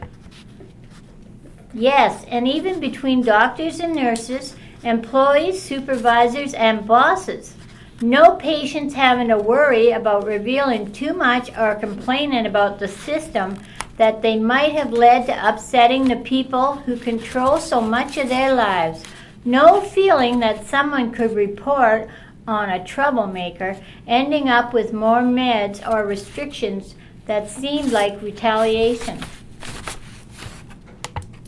The components for this unit are three short plays, fictionalized accounts of mental health experiences in the past, present, and imagined future.